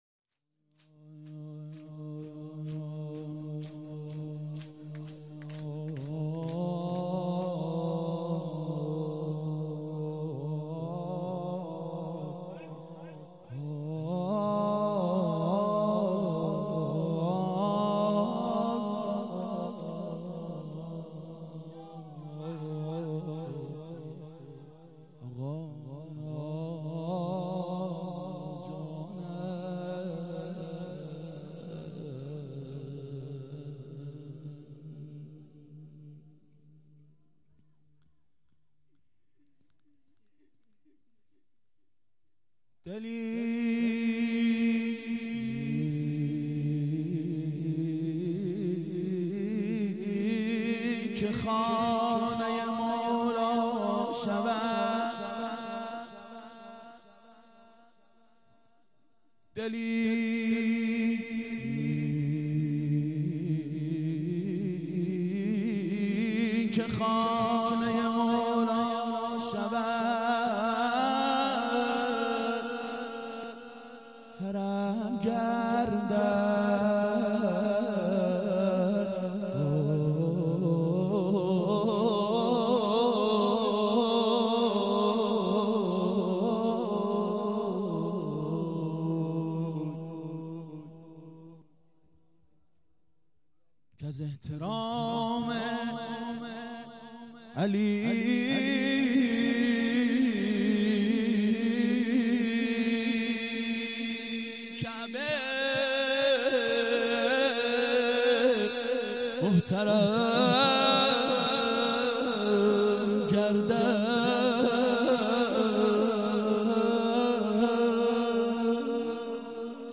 • دهه اول صفر سال 1391 هیئت شیفتگان حضرت رقیه سلام الله علیها (شام غریبان)